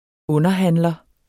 Udtale [ ˈɔnʌˌhanˀlʌ ]